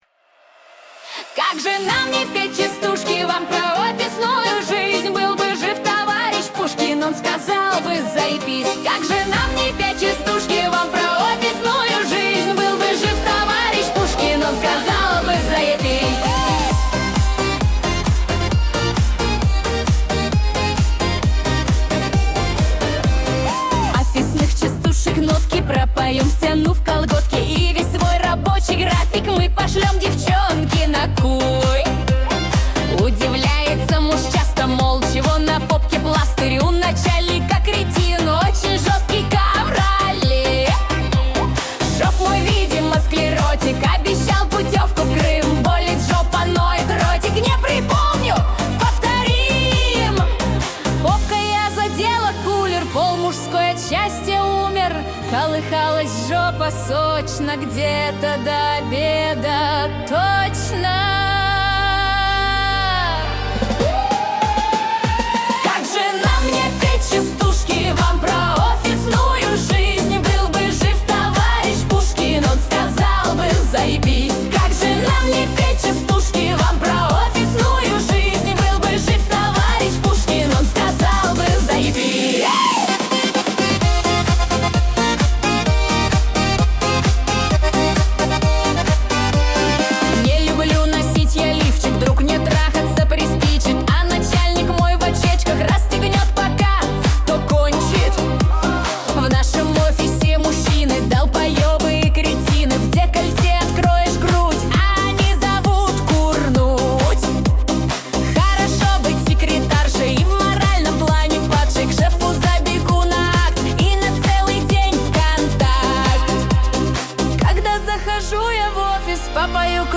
- Темп: Быстрый, энергичный.
- Ритм: Танцевальный, с акцентом на сильную долю.
- Стиль: Разговорный, с элементами речитатива.
- Диапазон: Средний, без сложных вокальных партий.